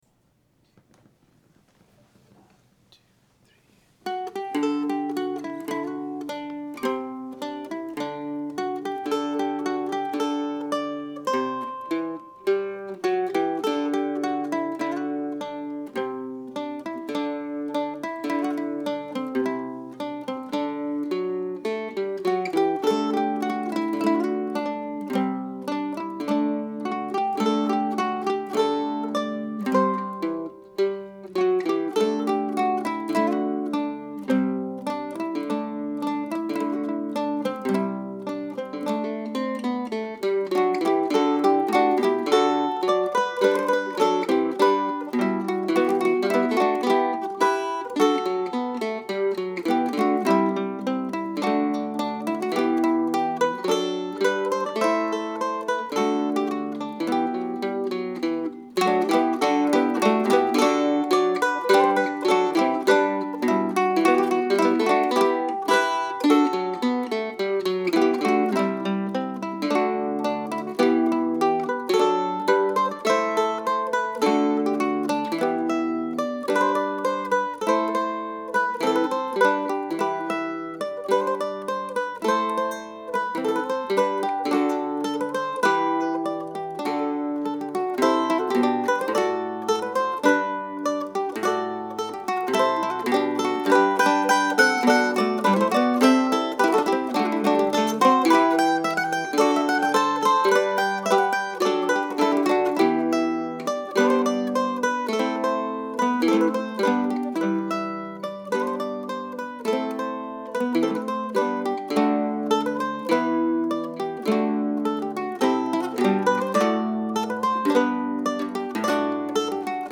(for mandolin quartet or ensemble)
MP3 (home recording with 3 mandolins and 1 octave mandolin)